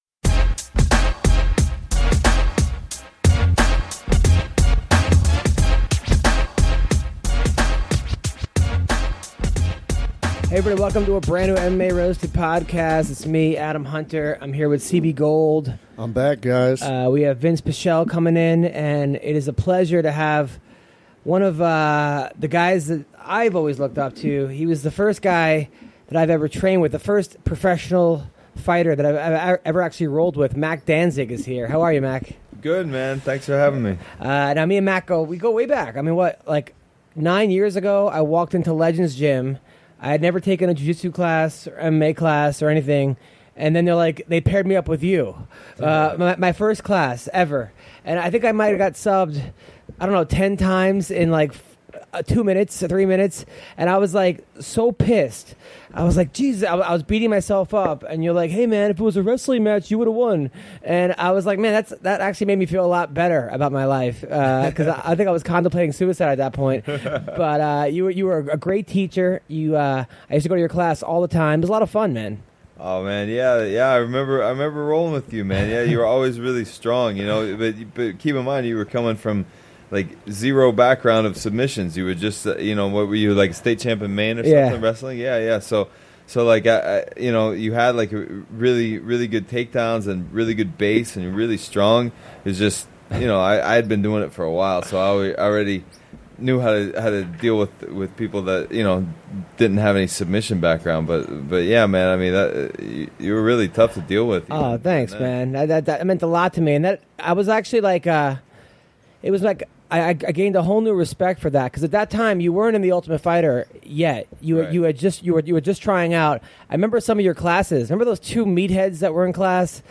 audio warning One of the mics is handheld and does not have a mic stand, so you can hear when it is being moved around or fondled. Besides that and the bird in first 10 minutes, every thing should be ok!